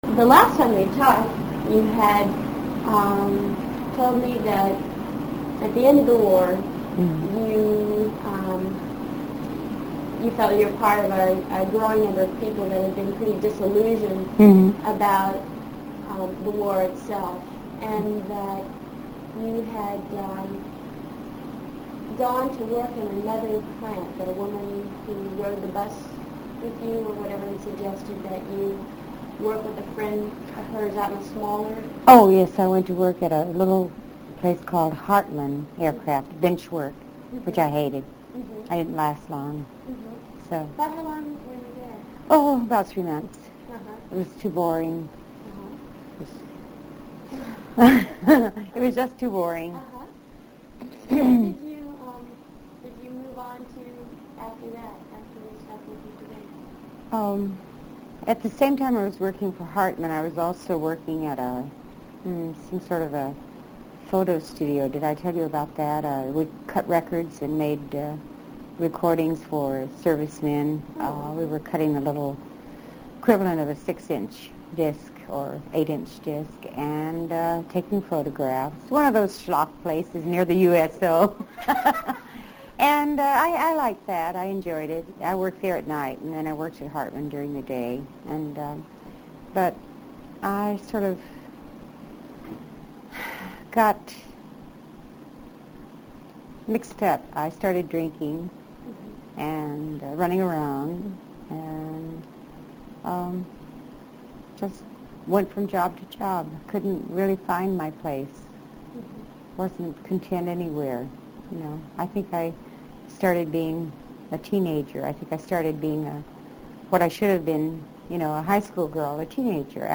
She remained an enthusiastic participant and cooperative narrator. 9/15/1981